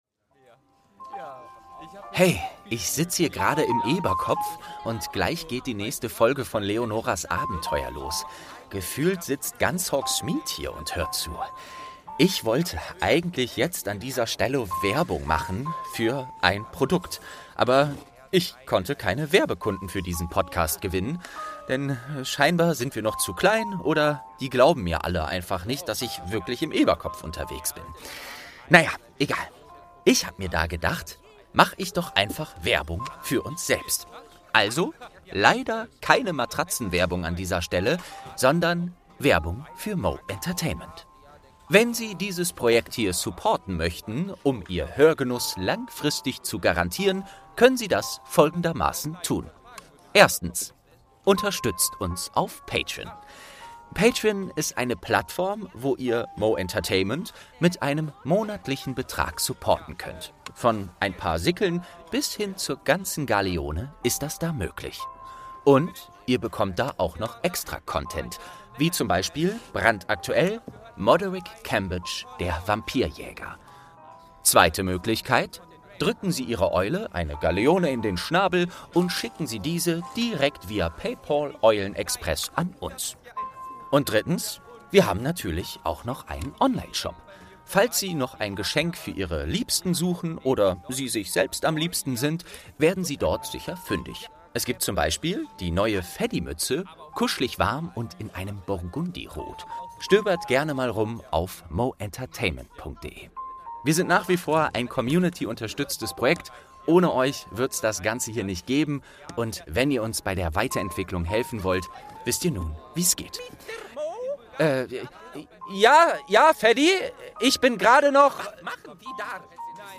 23. Türchen | Dumbledores Gemälde - Eberkopf Adventskalender ~ Geschichten aus dem Eberkopf - Ein Harry Potter Hörspiel-Podcast Podcast